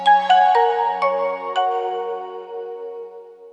In addition, thanks to ADPCM sound support, there was a voice recorder function and, interestingly, a startup sound (later used on the 6510), which can be heard here:
For comparison, the original audio before it was compressed for the device sounds like this: